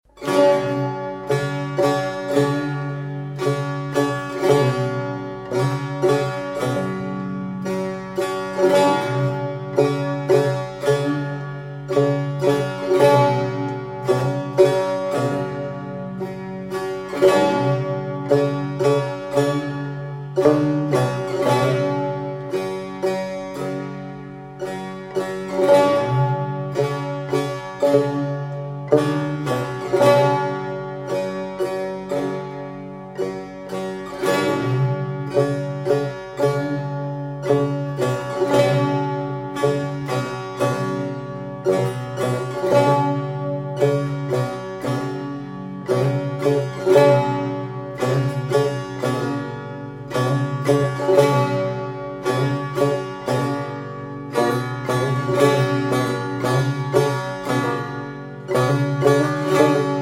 Setar
Kamanche
Tar